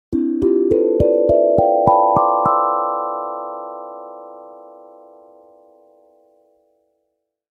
Mystery-accent-sound-effect.mp3